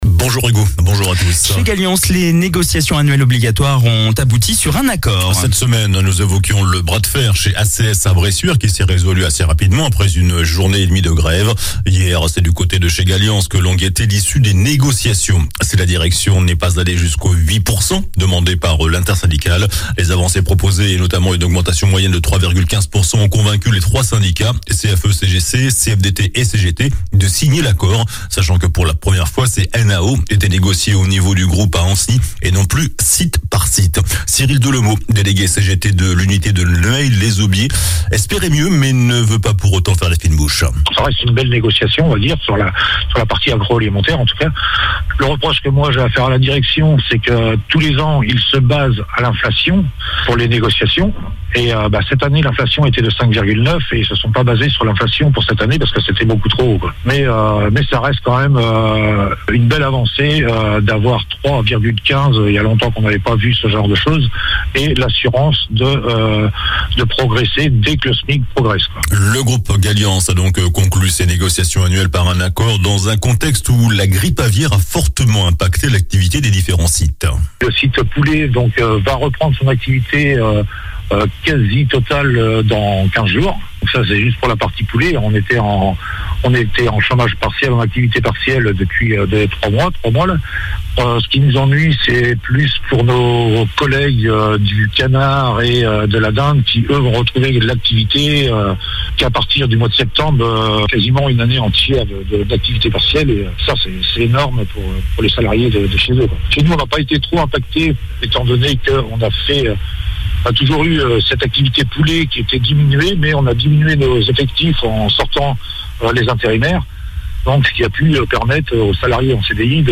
JOURNAL DU SAMEDI 08 AVRIL